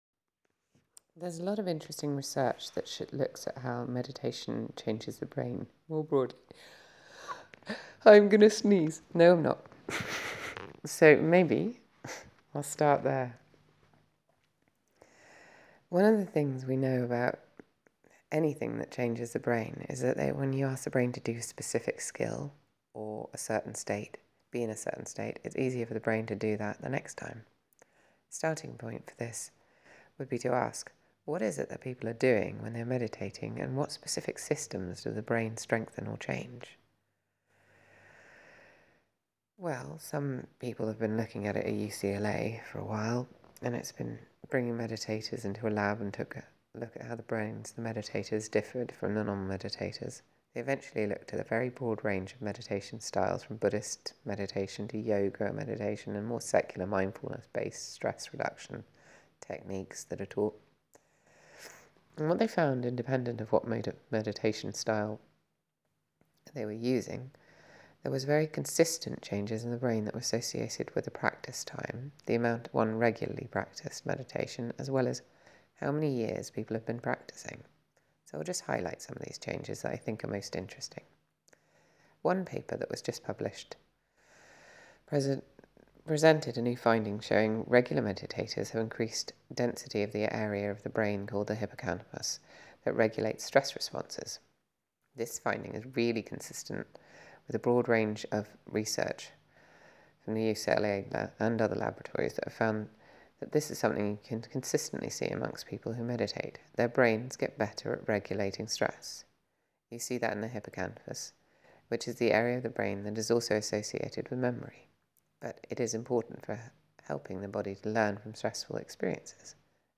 Talks and Meditation